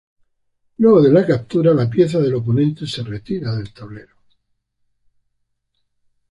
/opoˈnente/